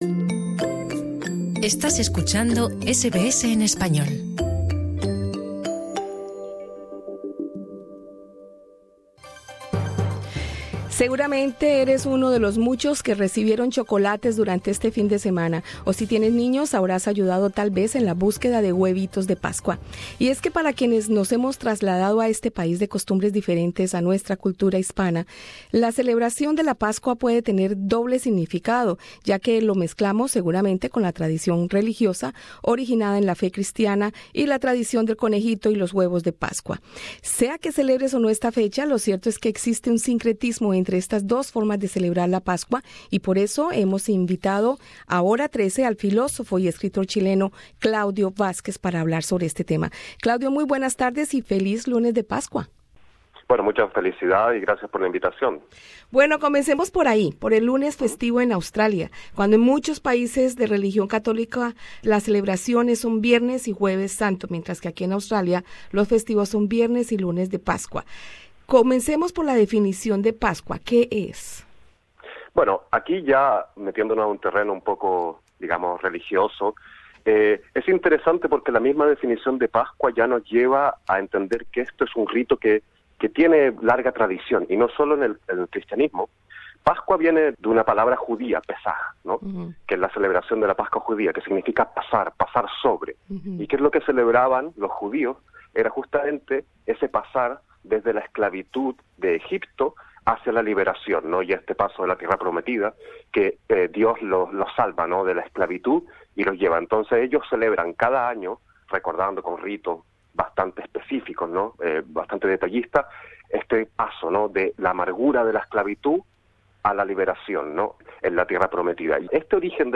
Escucha el podcast con la entrevista con el filósofo y escritor